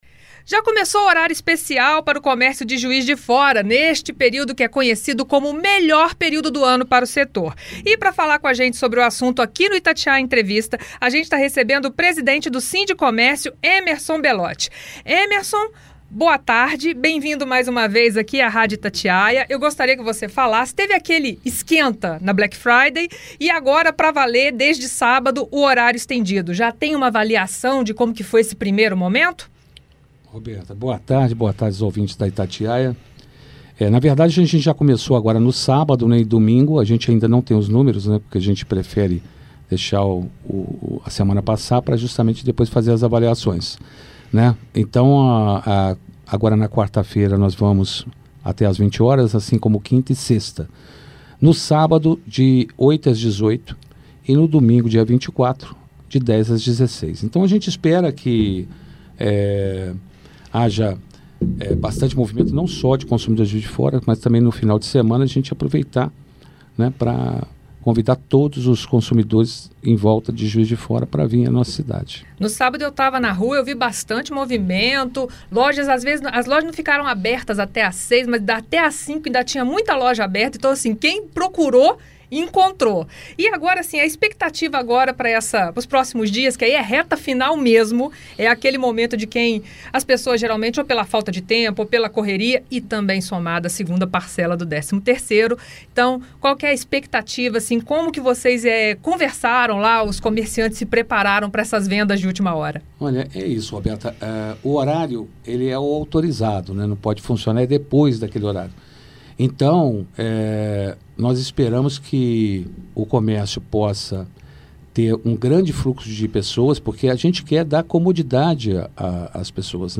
Itatiaia Entrevista